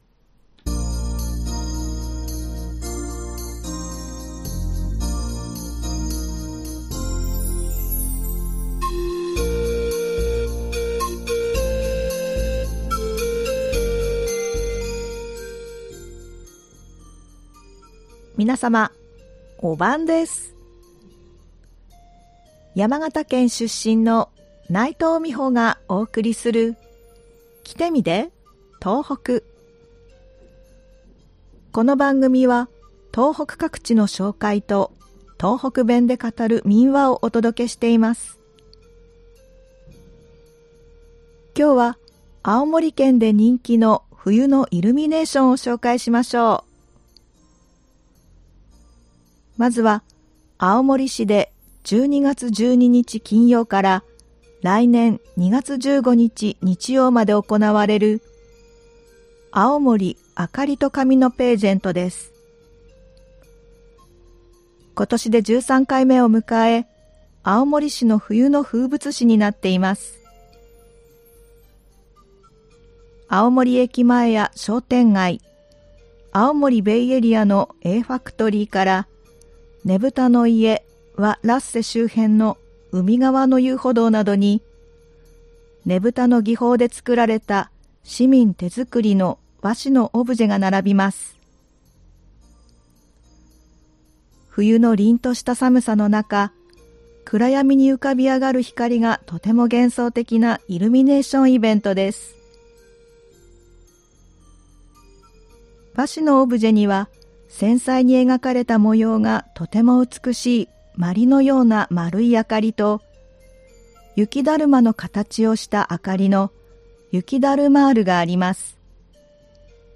ではここから、東北弁で語る民話をお送りします。